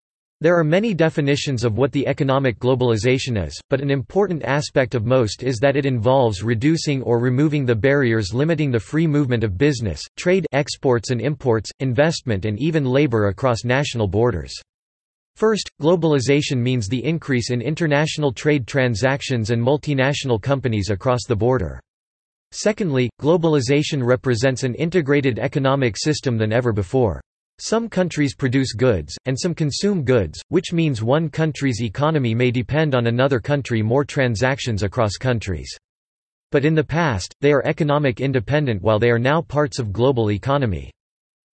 You will hear a short lecture.